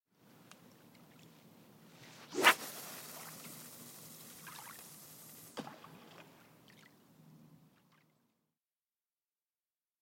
Шум дальнего заброса удочки